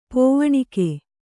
♪ pōvaṇike